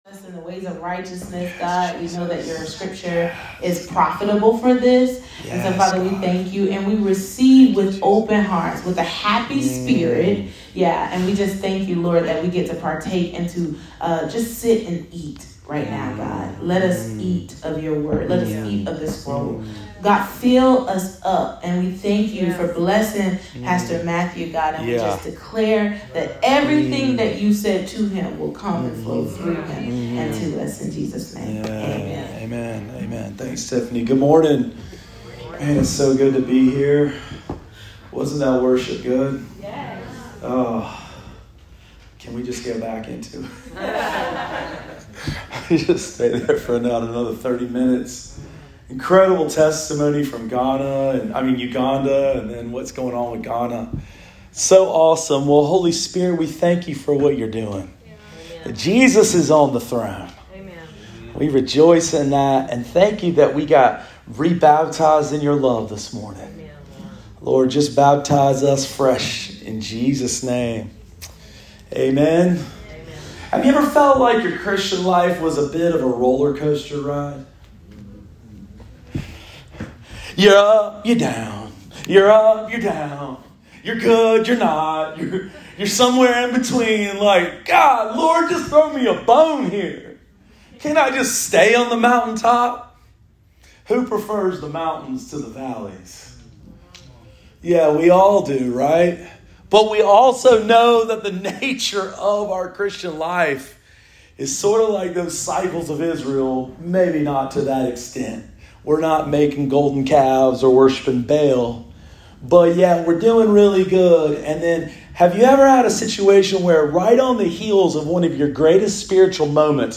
Sermon of the Week: 11/06/22 – RiverLife Fellowship Church